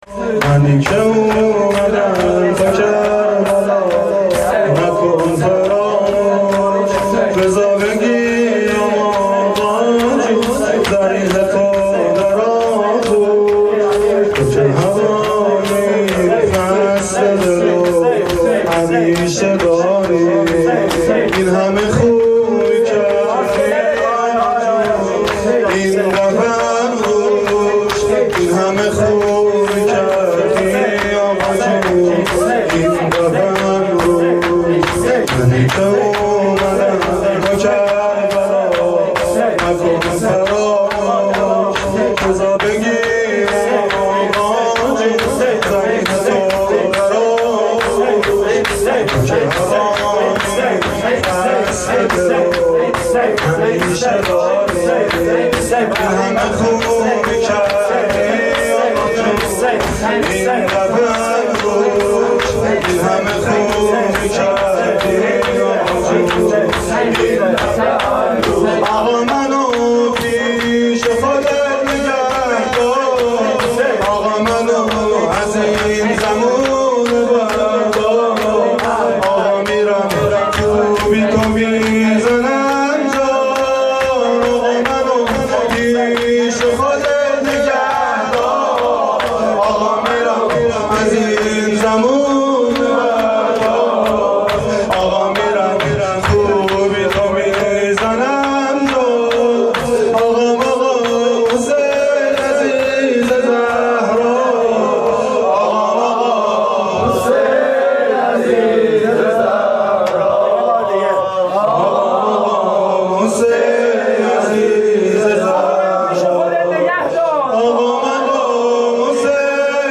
حسینیه, هیئت رایت الهدی کمالشهر
نوای فاطمیه
مداحی فاطمیه